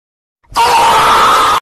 Emoji Disintegrating Sound Effect Free Download
Emoji Disintegrating